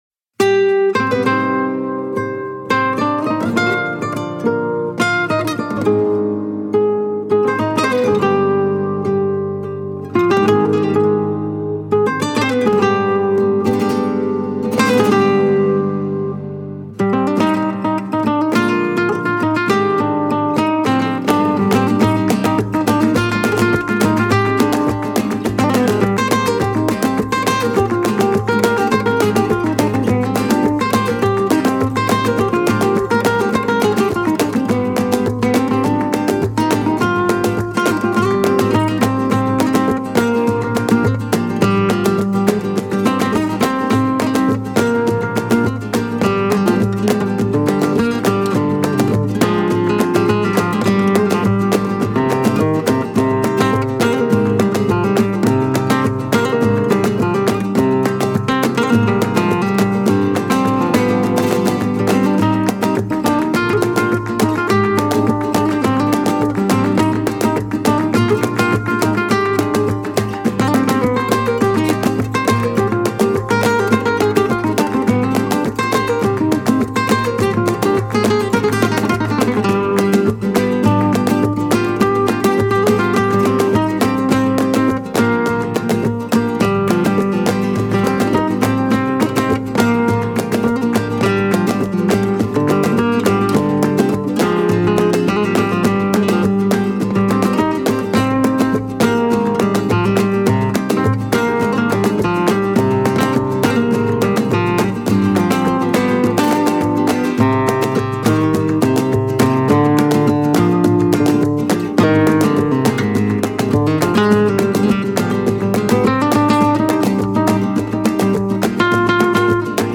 flamenco guitar